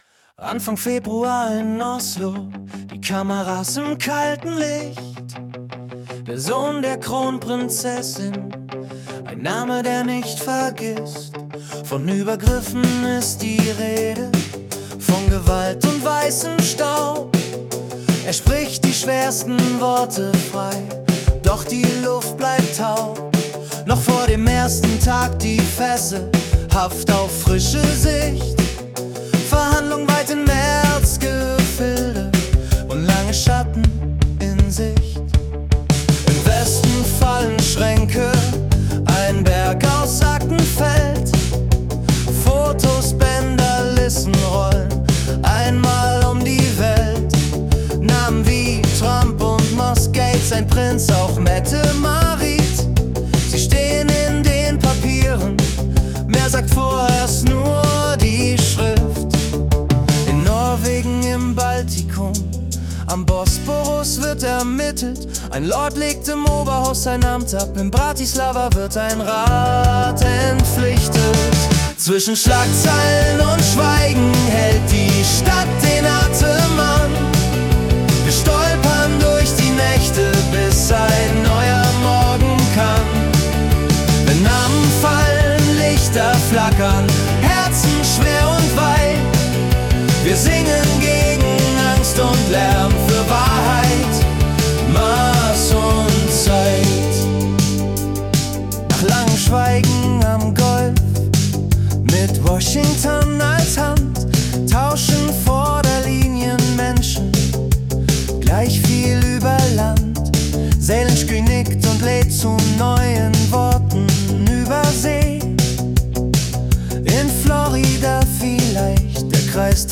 Februar 2026 als Singer-Songwriter-Song interpretiert.